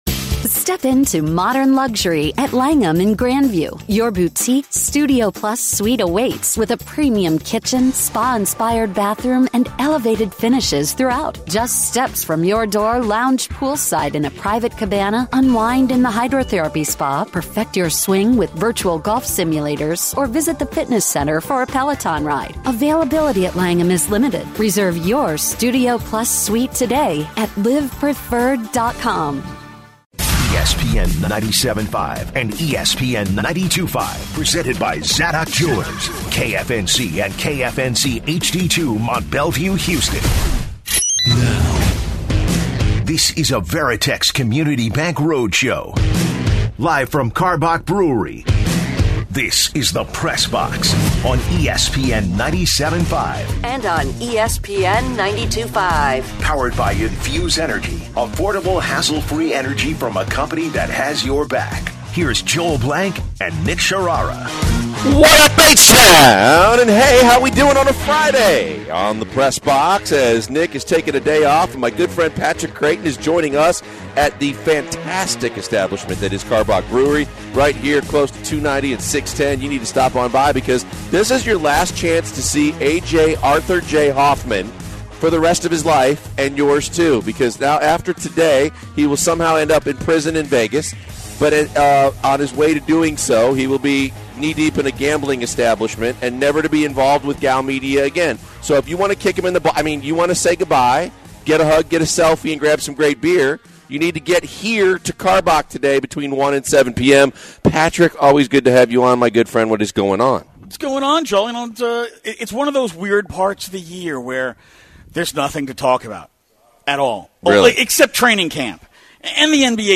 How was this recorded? from Karbach Brewing for our ESPN 97.5 Summer Circuit. In the first hour they talk about the fast approaching MLB Trade Deadline and all the moves from the past day and they talk about the NBA Draft and the Rockets picks to start rebuilding the franchise.